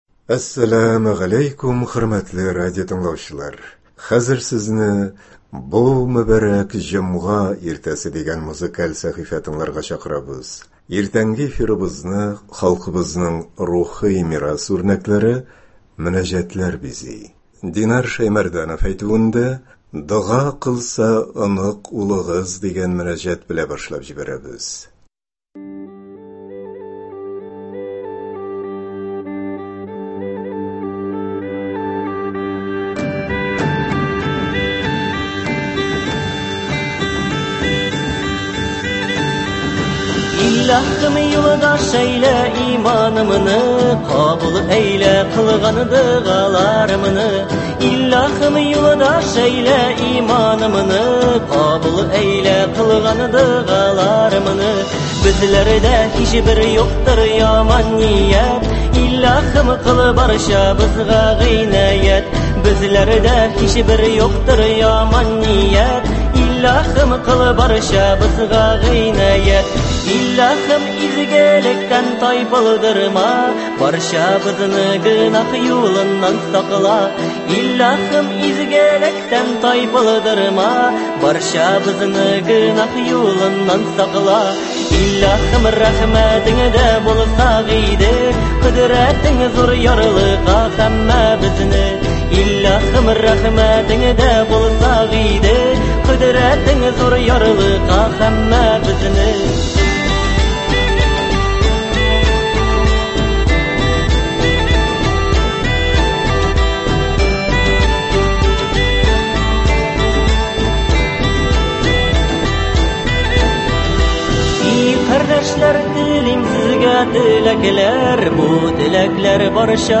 Иртәнге эфирыбызны халкыбызның рухи мирас үрнәкләре – мөнәҗәтләр бизи.